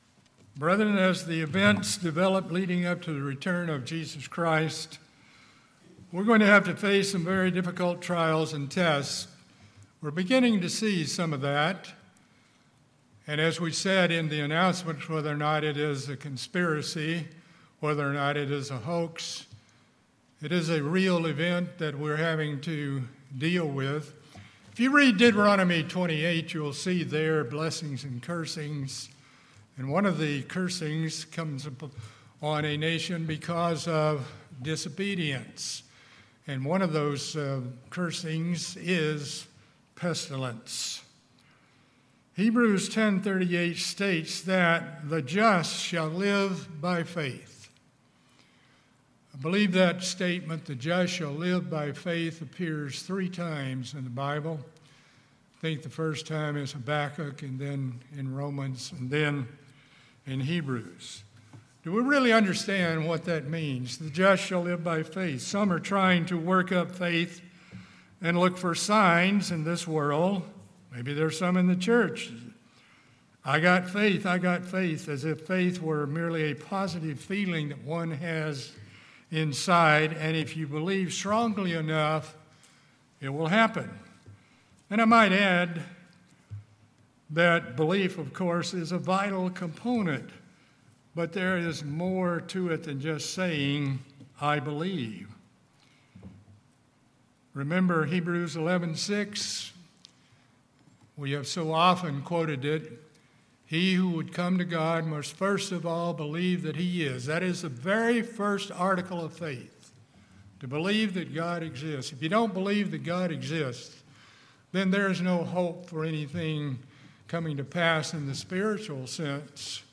In this sermon we will examine the origin, development and outcome of faith and what it means to live by faith.